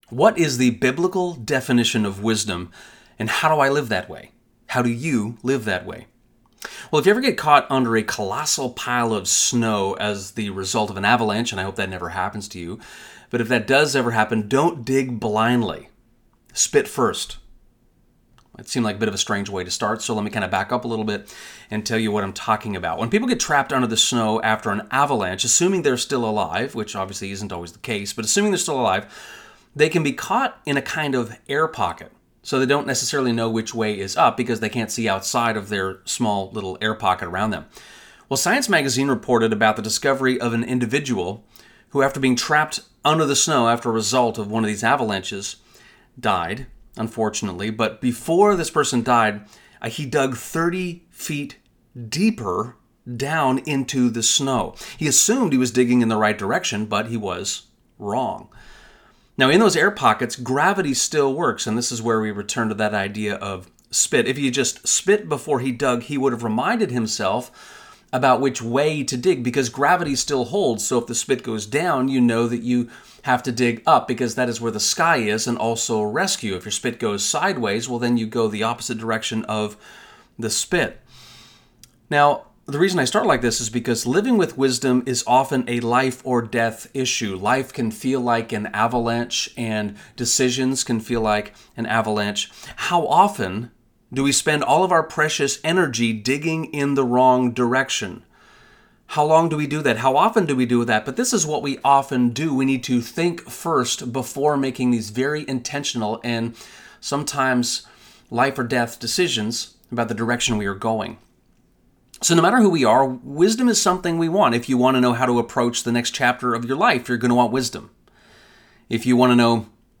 Sermon Notes
This is an audio recording created following the service.